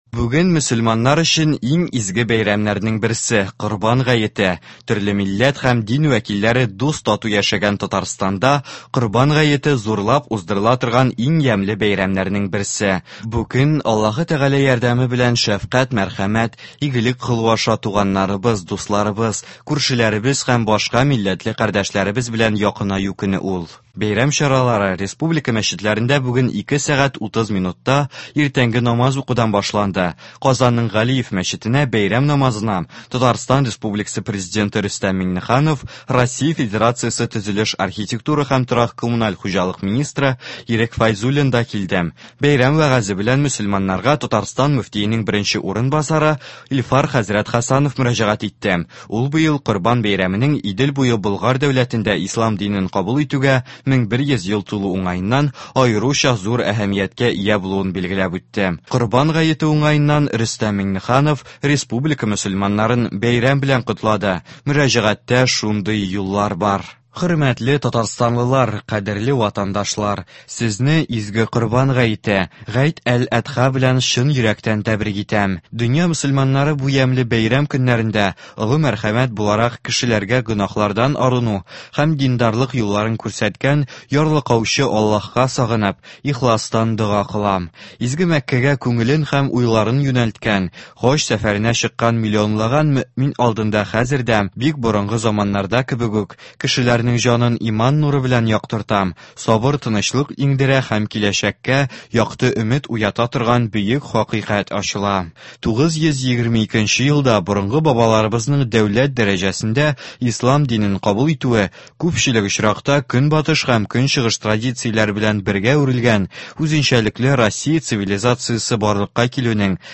Яңалыклар (09.07.22)